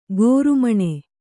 ♪ gōru maṇe